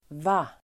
Uttal: [va:(d)]